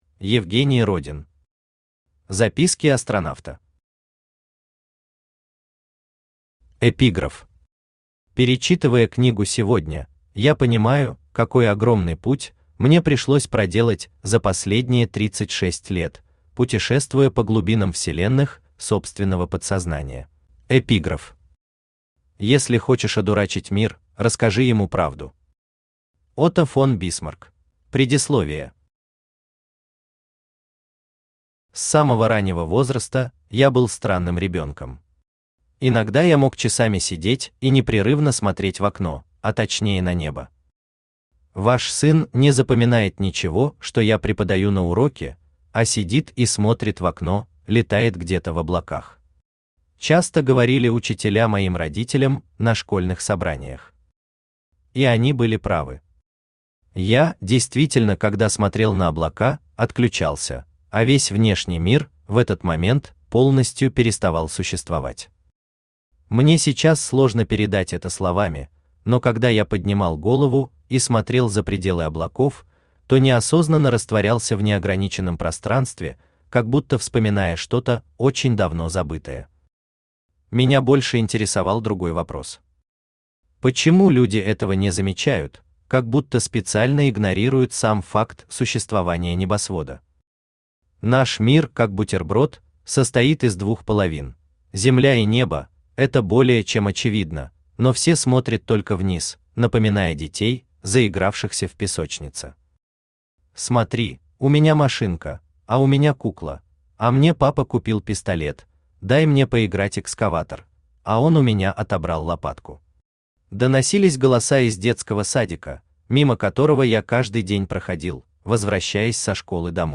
Аудиокнига Записки Астронавта | Библиотека аудиокниг
Aудиокнига Записки Астронавта Автор Евгений Родин Читает аудиокнигу Авточтец ЛитРес.